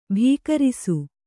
♪ bhīkarisu